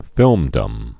(fĭlmdəm)